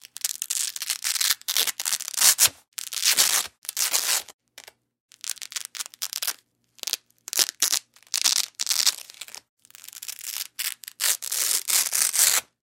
描述：一些基本的魔术师声音。
Tag: 魔术贴 翻录 粗糙 划痕